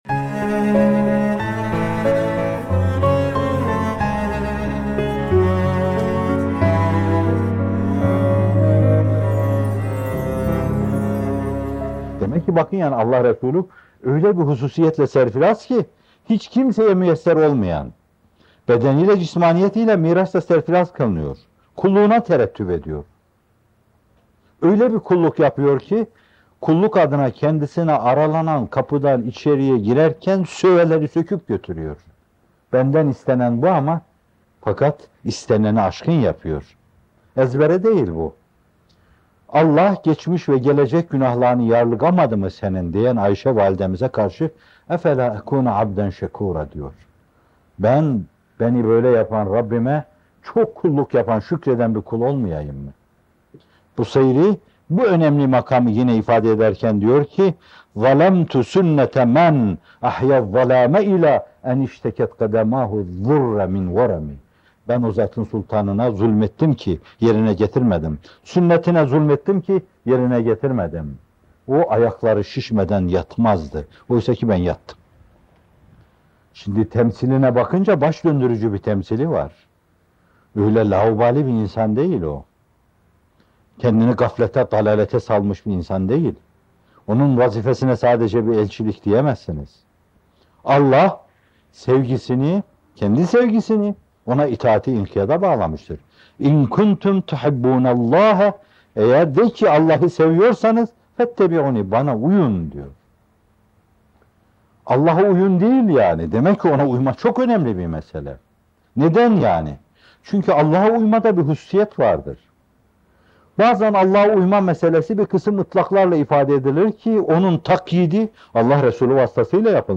Efendimiz’e (s.a.s) İttibâ’ ve İnkıyâd - Fethullah Gülen Hocaefendi'nin Sohbetleri